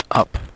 Recovered signal (M=4, Mu=0.1)
• There wasn't any perceptible difference between recovered signals with different Mu.